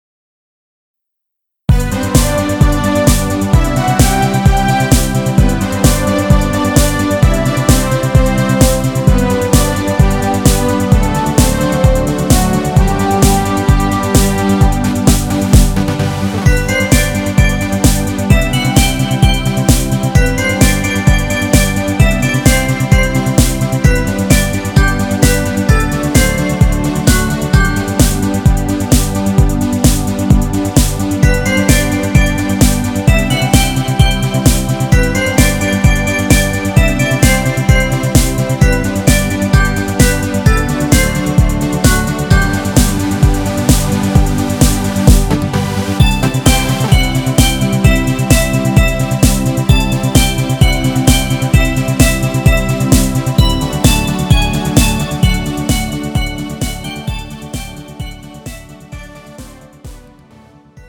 음정 G 키
장르 가요 구분 Pro MR